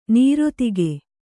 ♪ nirōdhaka